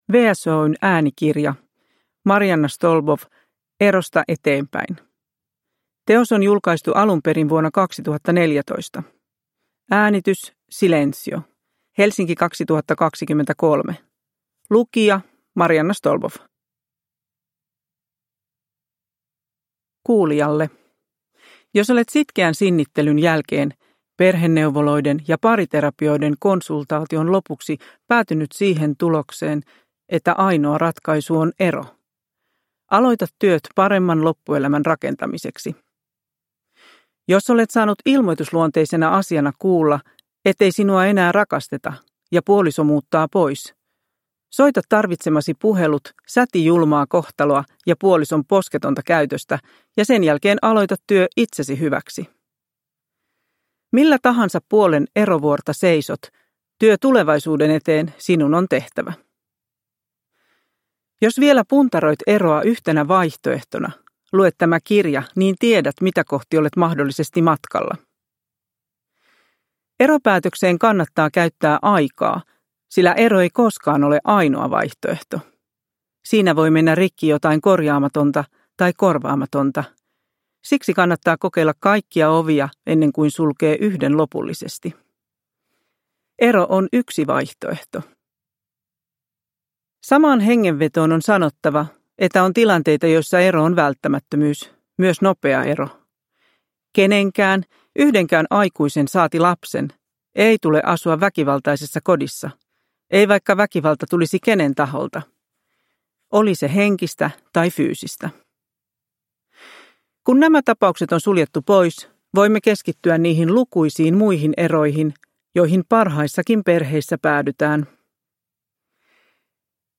Erosta eteenpäin – Ljudbok – Laddas ner